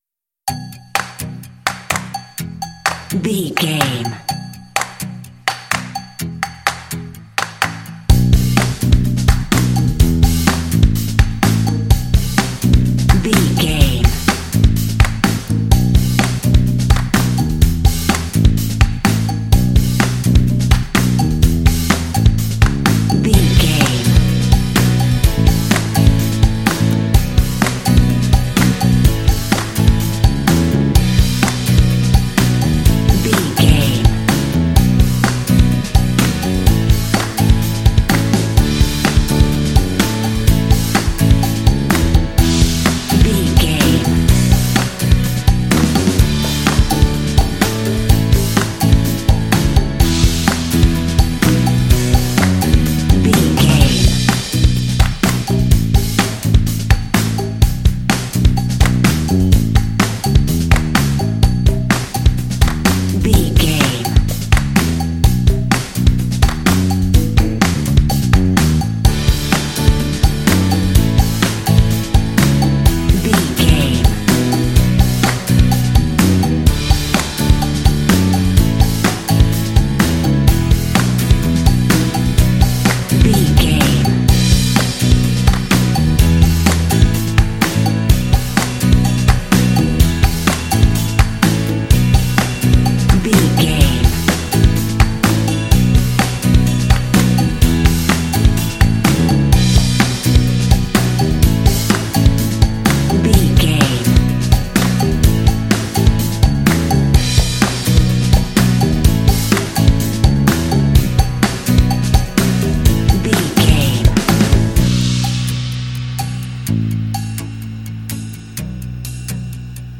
This fun and lighthearted track features a funky organ.
Uplifting
Mixolydian
bouncy
electric guitar
drums
percussion
organ
bass guitar
rock
alternative
indie
blues